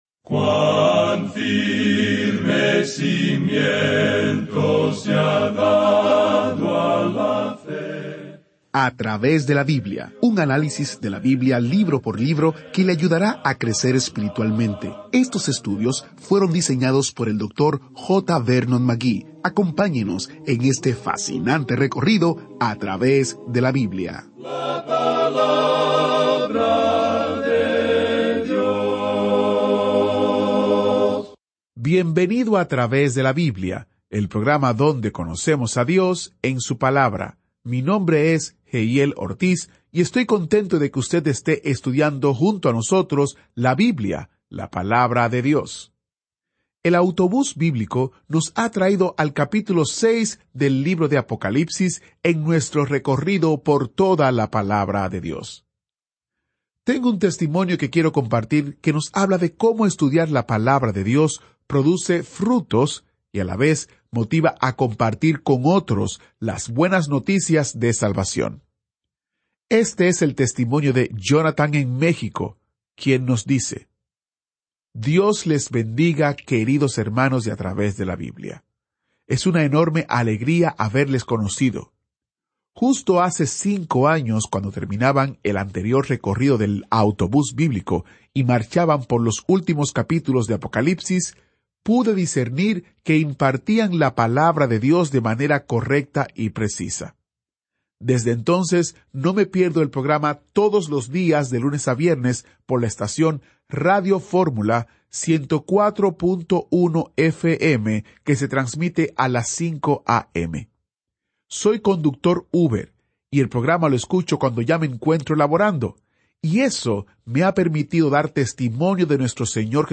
1 Apocalipsis 6:1-8 24:02 Play Pause 2h ago 24:02 Play Pause Reproducir más Tarde Reproducir más Tarde Listas Me gusta Me gusta 24:02 24 Enero 2025 A Través de la Biblia es un estudio bíblico en audio que lleva al oyente a un recorrido a través de toda la Biblia en un período de 5 años.